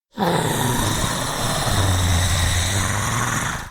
zombie1.ogg